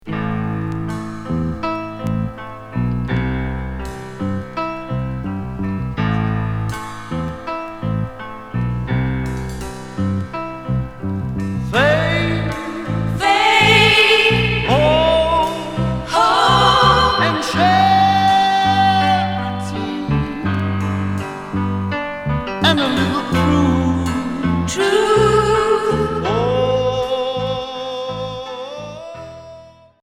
Pop progressif